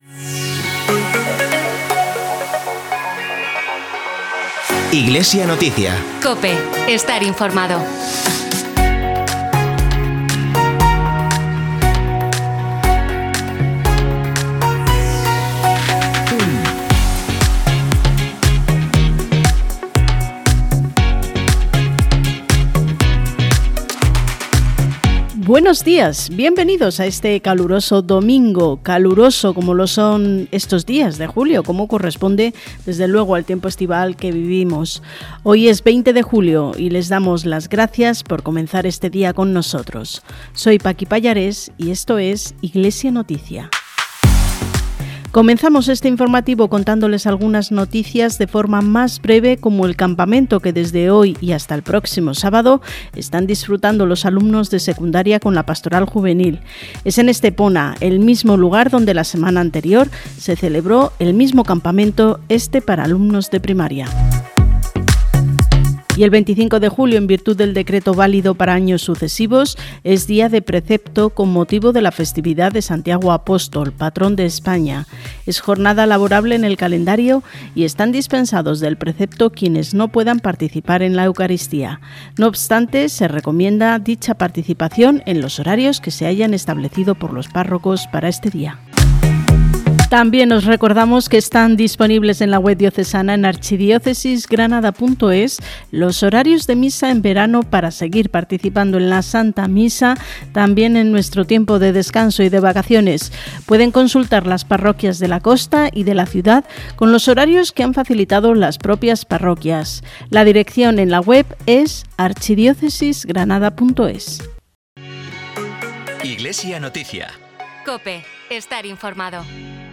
Programa emitido el 20 de julio en COPE Granada y COPE Motril.